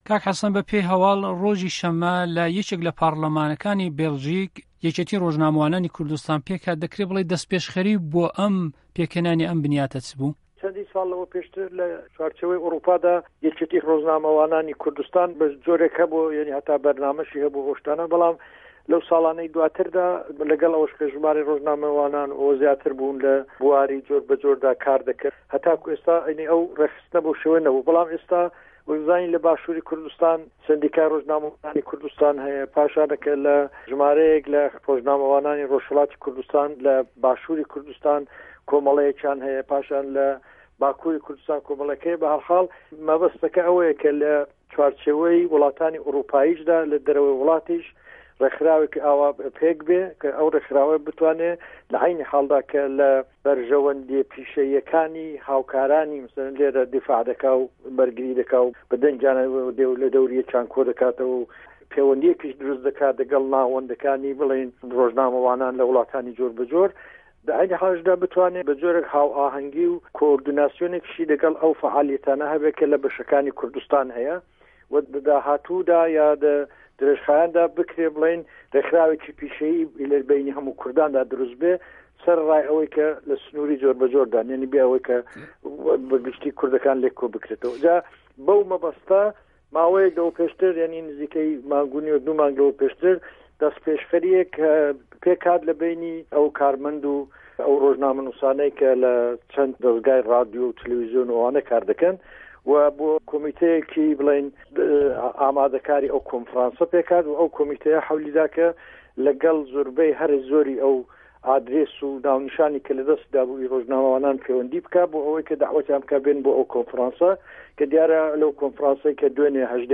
وتو و وێژی